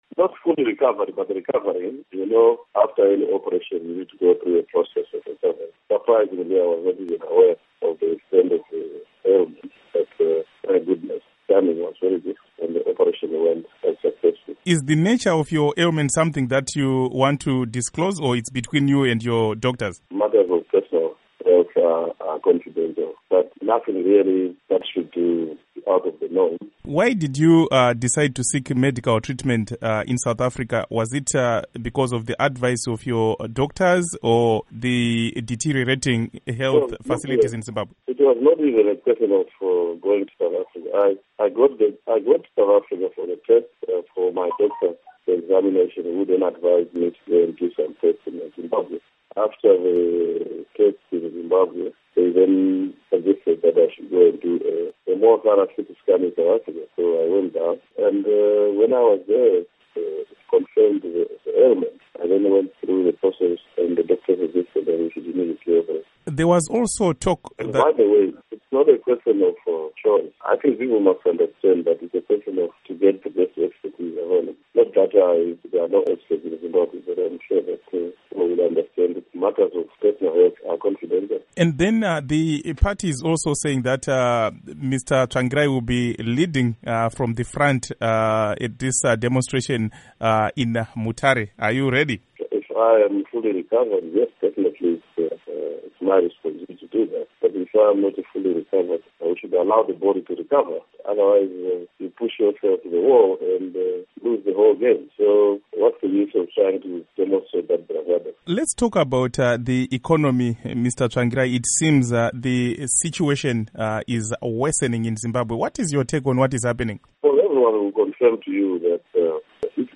Interview With Morgan Tsvangirai on Zimbabwe Crisis